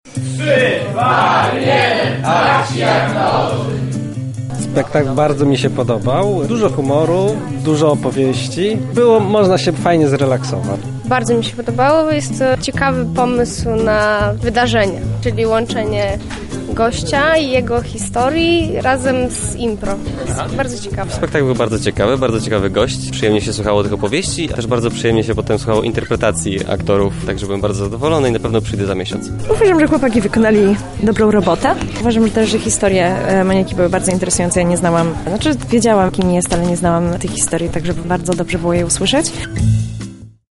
O tym, że jest to ciekawy sposób spędzania wolnego czasu przekonywali uczestnicy wydarzenia.